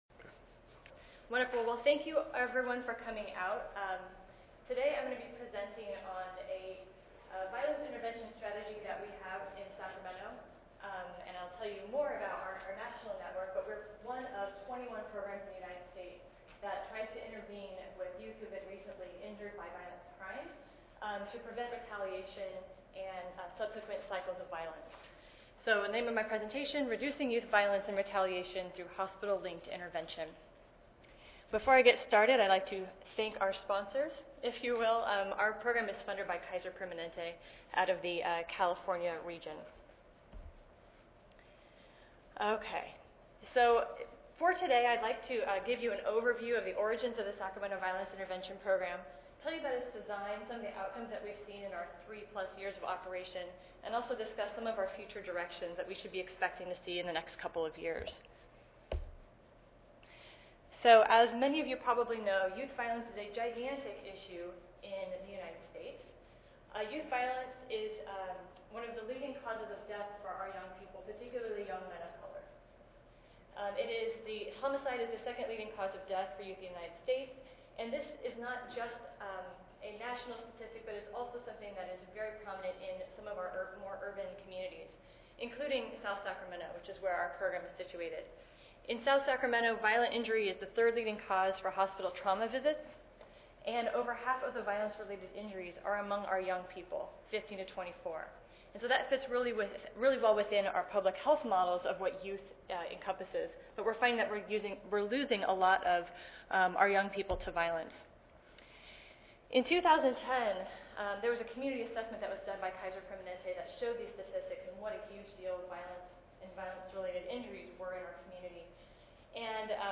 141st APHA Annual Meeting and Exposition (November 2 - November 6, 2013): BCHW Special Student Panel - Violence among African americans: What impact has hip hop and urban culture had on the rate of violence in African American communities?
3335.0 BCHW Special Student Panel - Violence among African americans: What impact has hip hop and urban culture had on the rate of violence in African American communities?